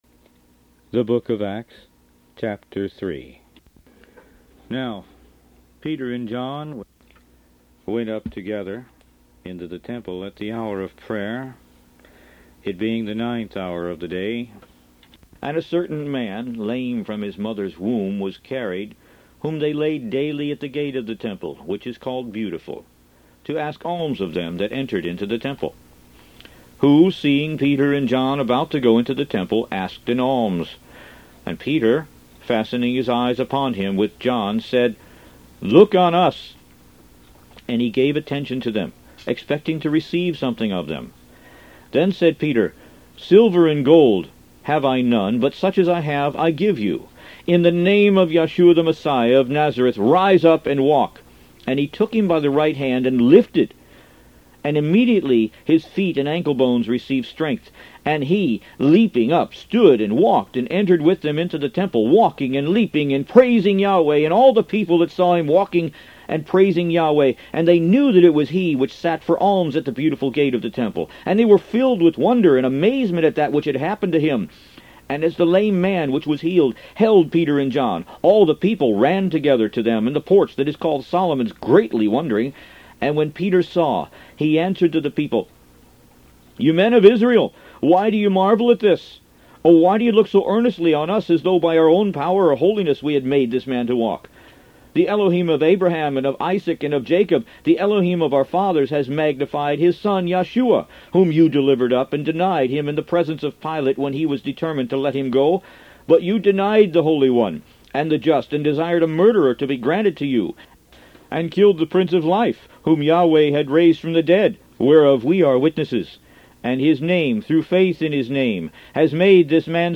Root > BOOKS > Biblical (Books) > Audio Bibles > Messianic Bible - Audiobook > 05 The Book Of Acts